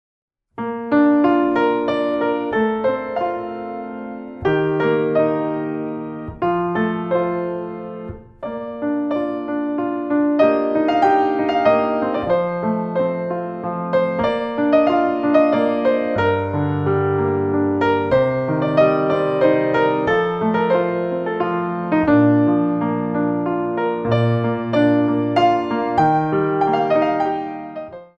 4 bar intro 3/4
64 bars